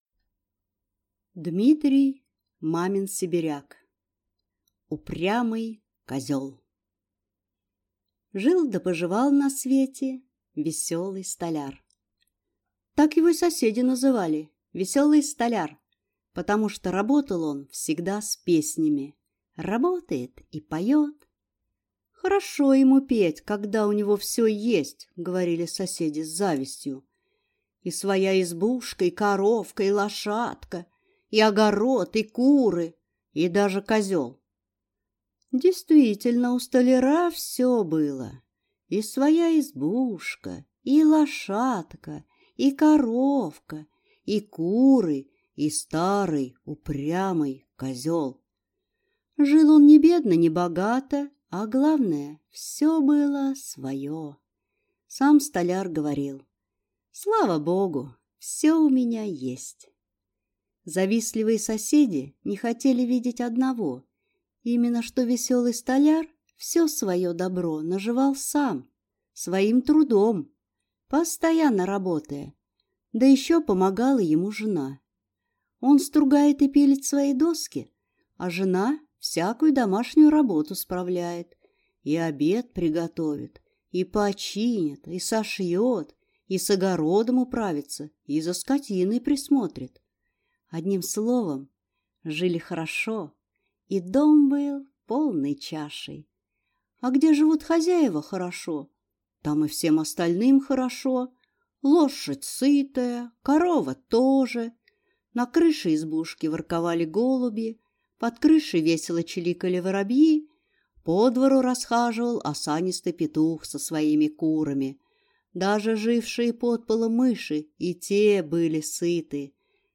Аудиокнига Упрямый козел | Библиотека аудиокниг